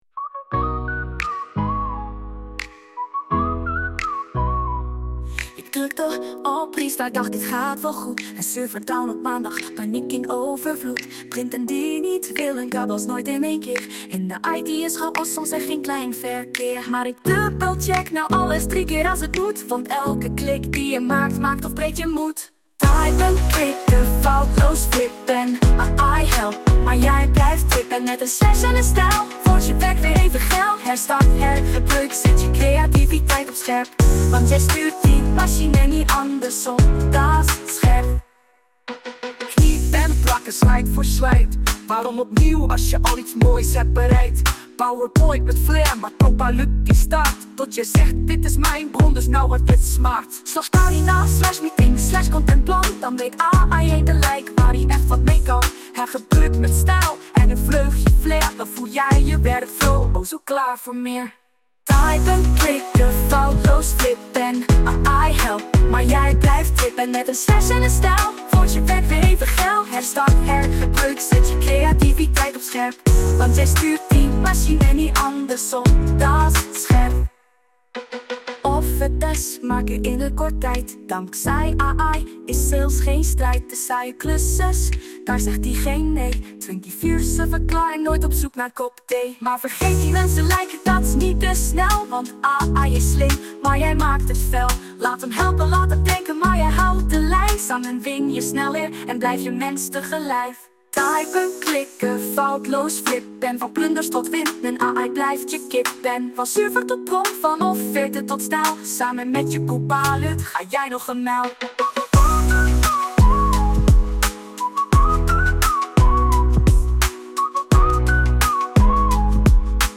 Dit lied is volledig met AI gegenereerd. De teksten zijn afkomstig van de interviews van aflevering 3.